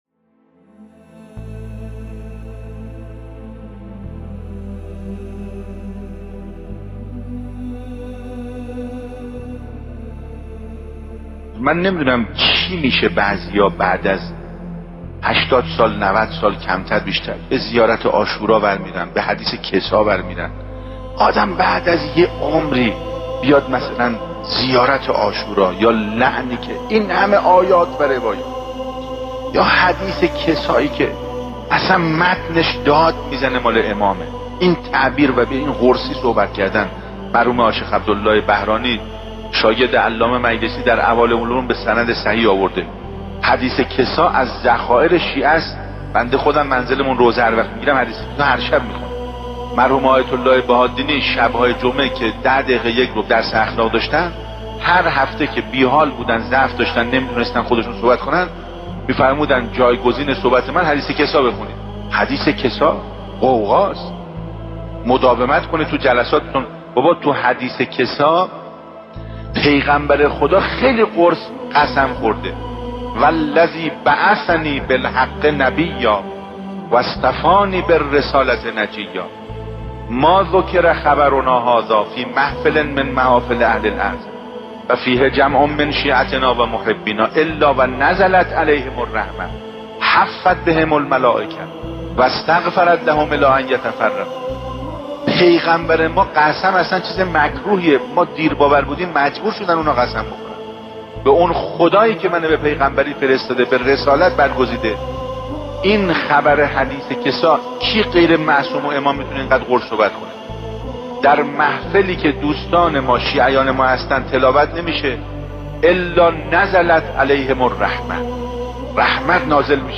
شهادت حضرت زهرا (س) آموزه‌ای متعالی در باب دفاع از آرمان‌های دینی و ارزش‌های اسلام است. ایکنا به مناسبت ایام سوگواری شهادت دخت گرامی آخرین پیام‌آور نور و رحمت، مجموعه‌ای از سخنرانی اساتید اخلاق کشور درباره شهادت ام ابی‌ها (س) با عنوان «ذکر خیر ماه» منتشر می‌کند.